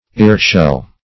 Ear-shell \Ear"-shell`\, n. (Zo["o]l.)